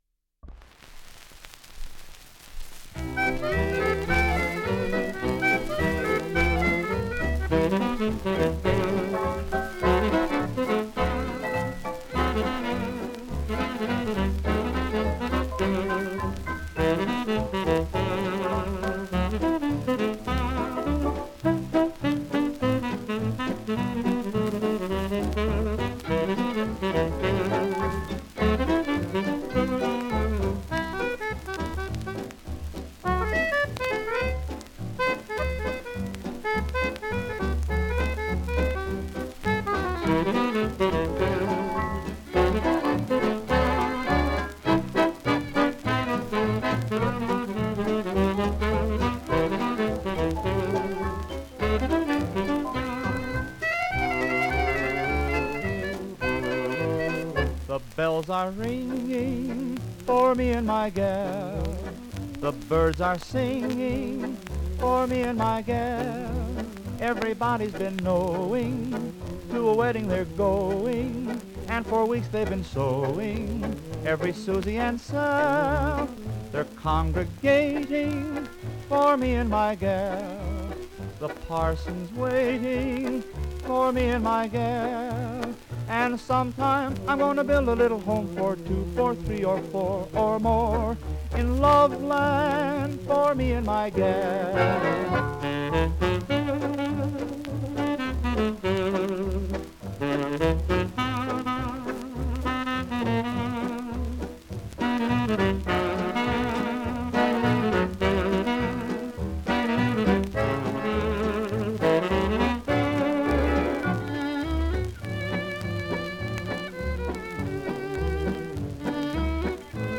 Genre: Fox-Trot.